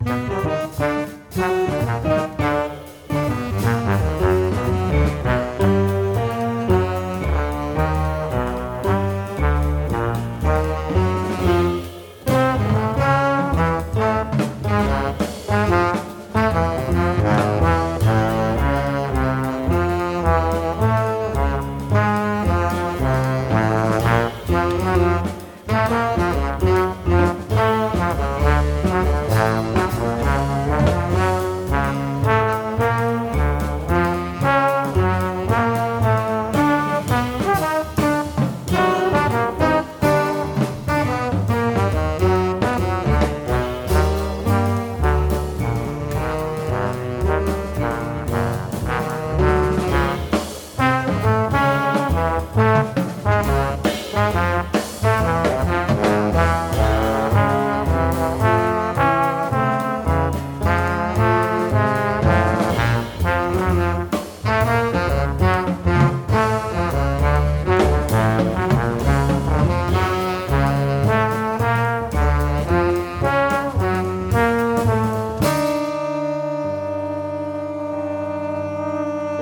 sax/cl
trb